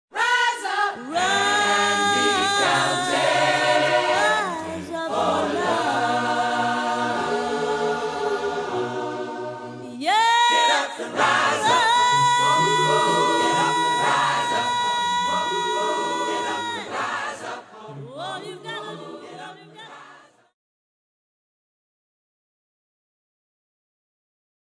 This song is included on the choir's first, self-titled CD